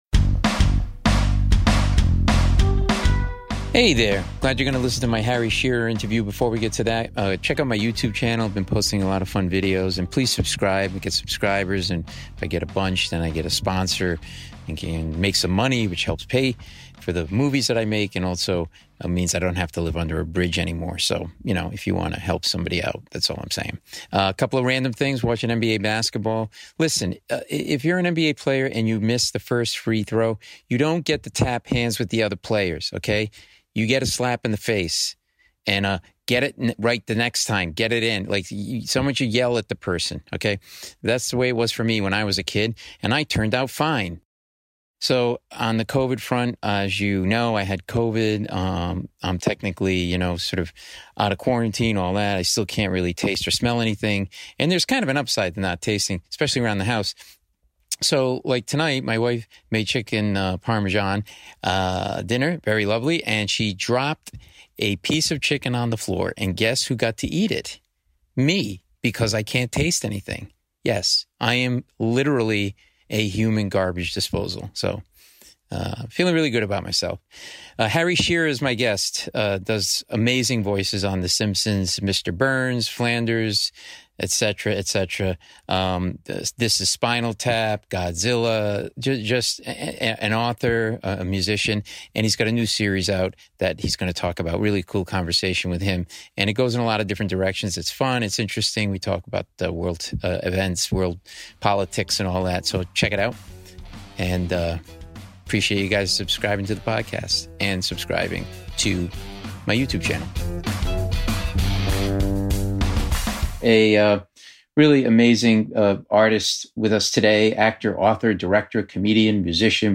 Harry Shearer - Voice of Mr. Burns, Waylon Smithers, Ned Flanders, Principal Skinner on "The Simpsons," "This is Spinal Tap," "The Fisher King" (Paul Mecurio interviews Harry Shearer; 19 Aug 2020) | Padverb